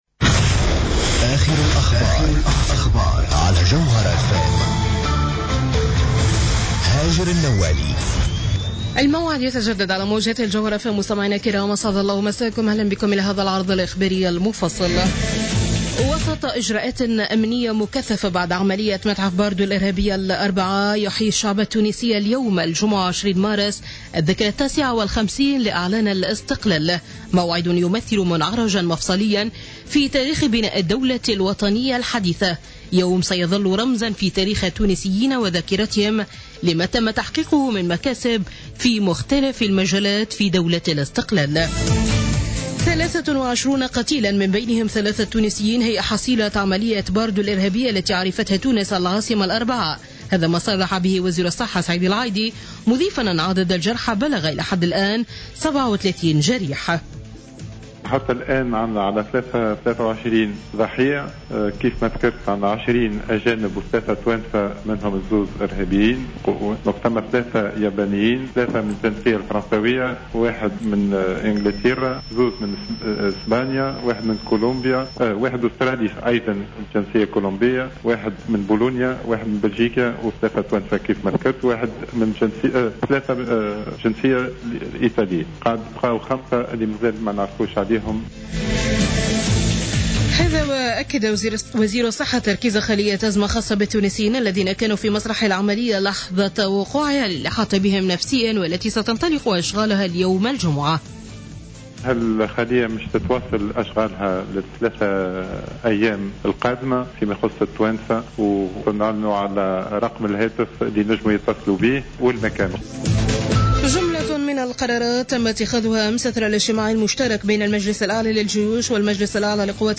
نشرة أخبار منتصف الليل ليوم الجمعة 20 مارس 2015